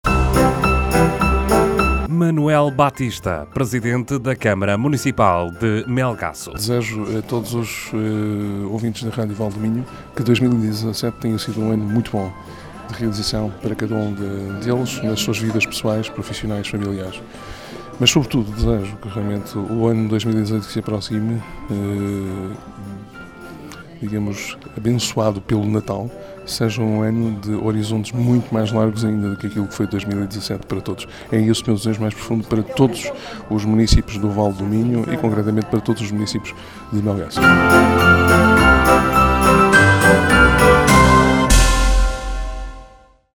Manoel Batista, Presidente da Câmara Municipal de Melgaço